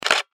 جلوه های صوتی
دانلود صدای دوربین 9 از ساعد نیوز با لینک مستقیم و کیفیت بالا